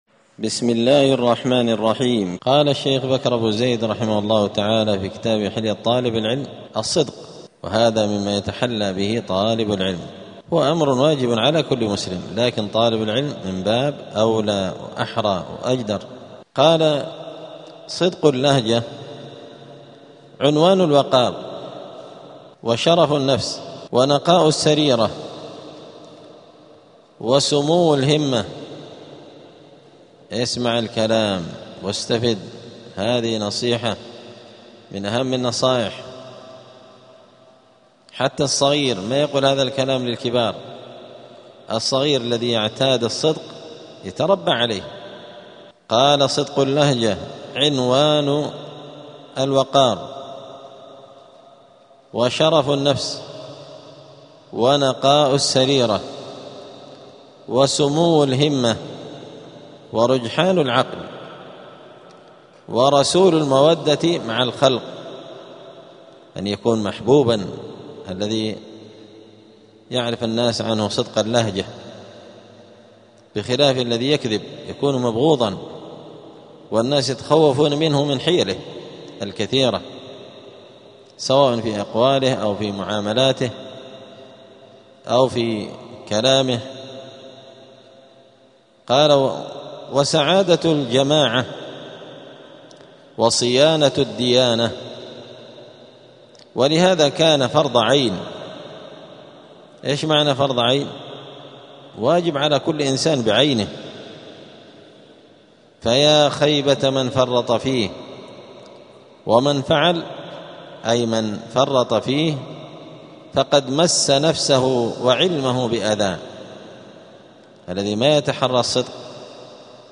*الدرس الثاني والسبعون (72) فصل آداب الطالب في حياته العلمية {اﻟﺼﺪﻕ}.*